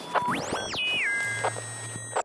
nerfs_psynoise5.ogg